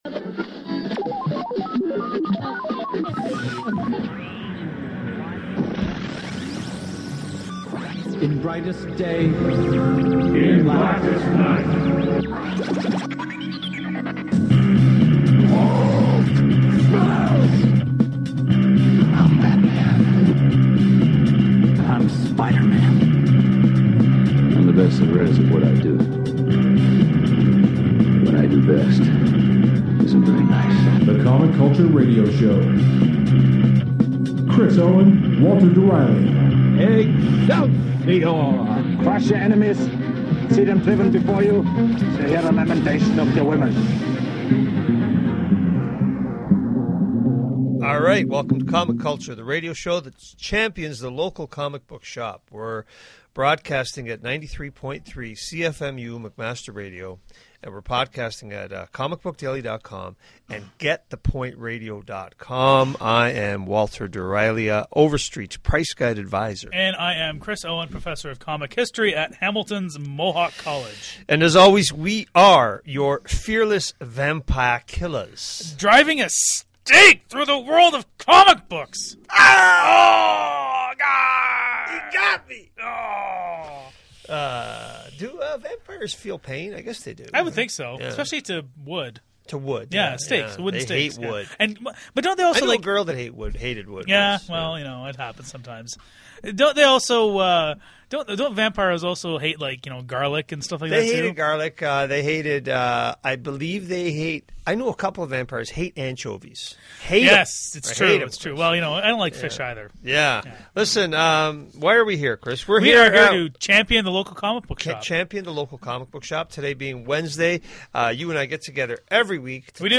The new DVD on on Stan Lee is discussed as is news of a Batman Lego movie. The guys also do a round up on some Halloween activity happening at local comic book shops. The Comic Culture mail bag is opened and new this week comics are discussed so sit back, relax and enjoy the Comic Culture radio show.